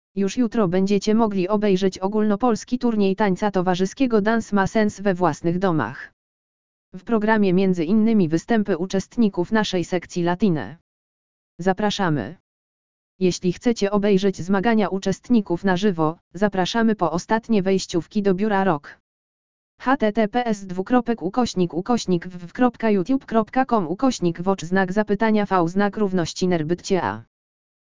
latina_lektor.mp3